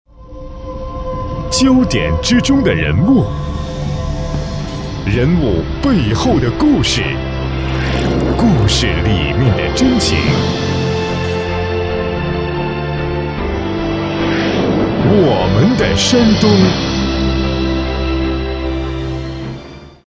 男国220_其他_栏目_节目片头我们的山东片头.mp3